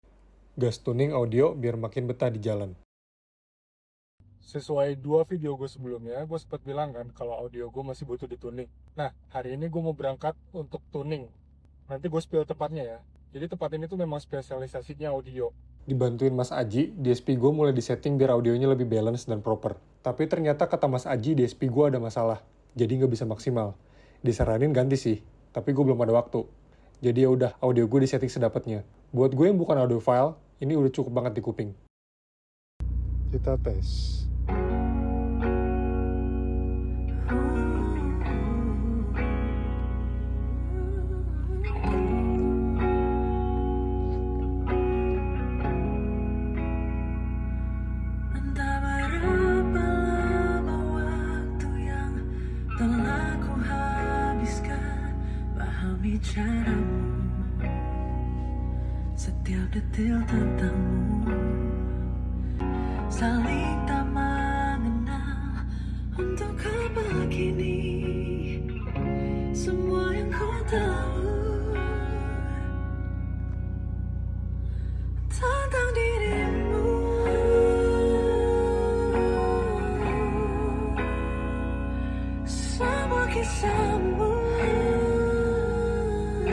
Sebenernya masih penasaran maksimalnya kayak gimana, dan gatel juga karna tweeter kadang bunyin kresek2, kenapa ya?